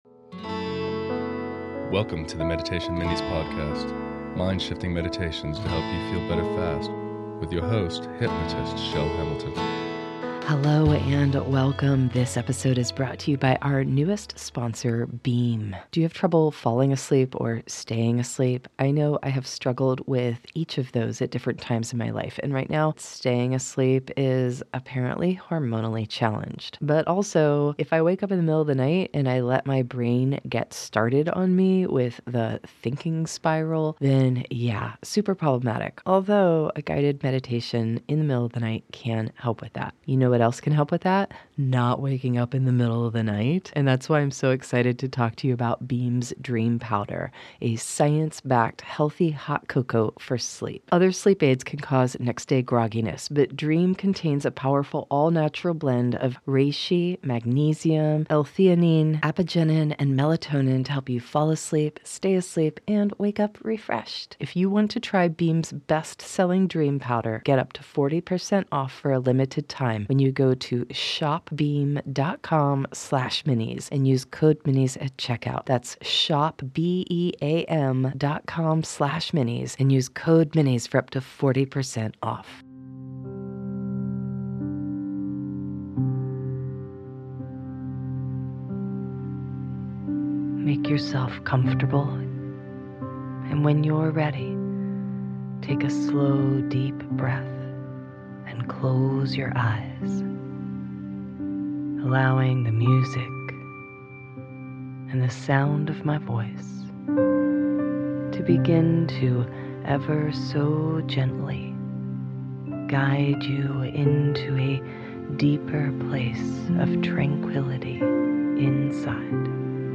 This is a great guided meditation to fall asleep to... or to use for deep relaxation.